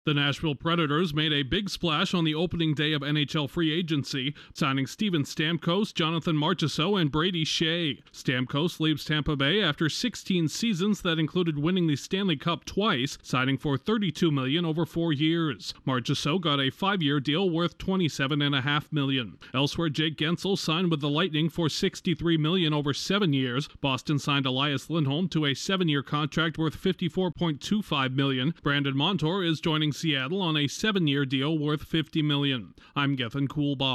NHL teams committed to a record $1.12 billion in salaries at the start of free agency. Correspondent